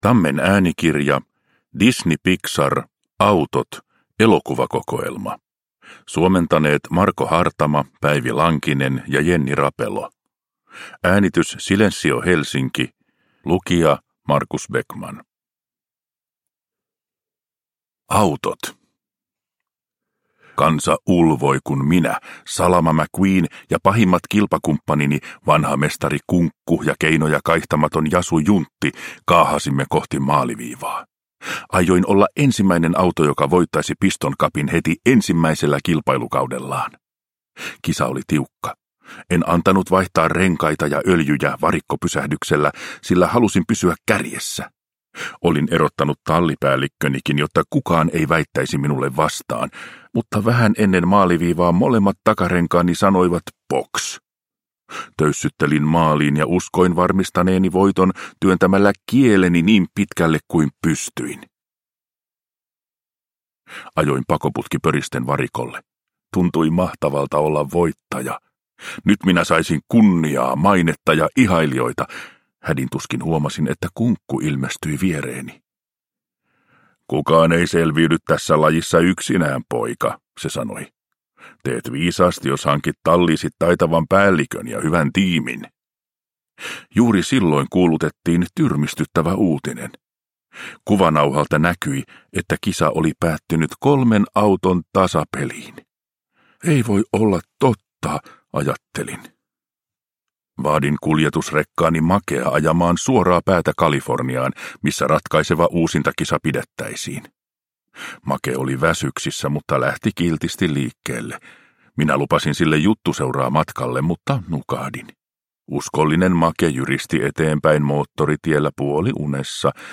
Pixar. Autot. Elokuvakokoelma – Ljudbok – Laddas ner